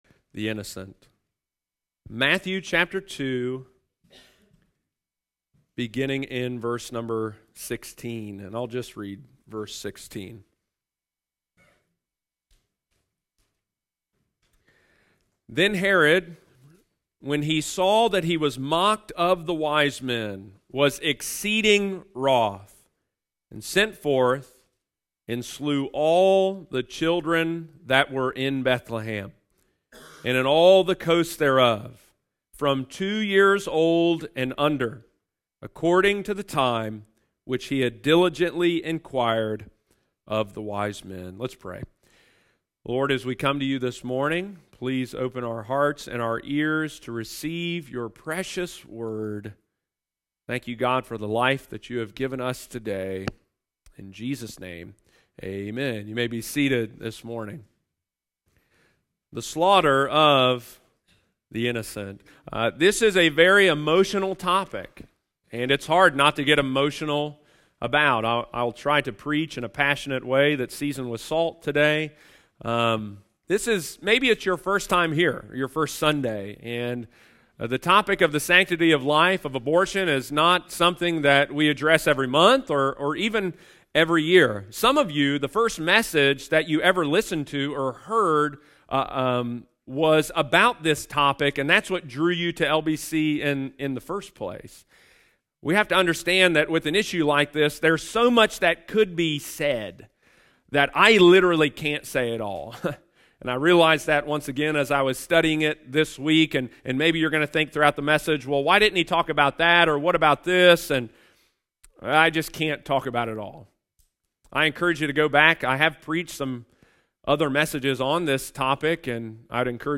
Slaughter Of The Innocent – Lighthouse Baptist Church, Circleville Ohio